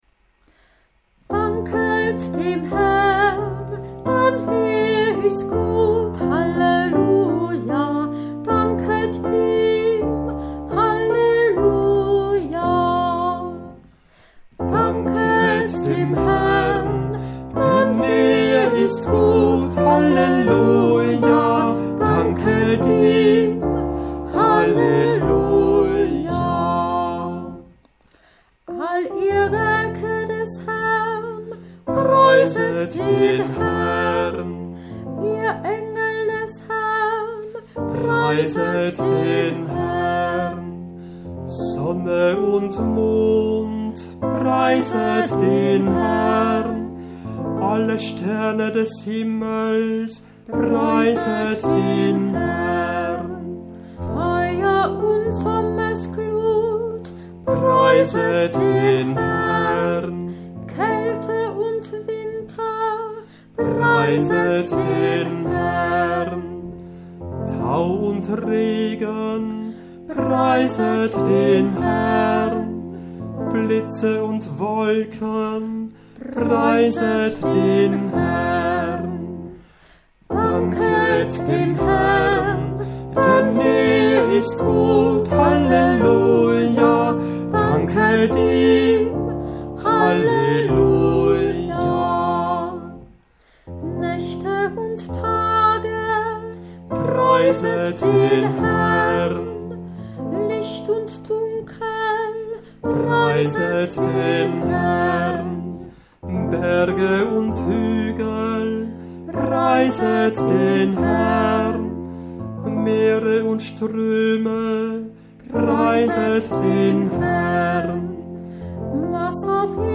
Gottesloblieder in zweistimmiger Bearbeitung